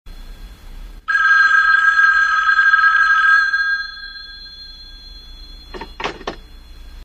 • OLD TELEPHONE RINGING AND PICK UP.mp3
Old Rotary telephone ringing in my grandmother's kitchen.
old_telephone_ringing_and_pick_up_1lo.wav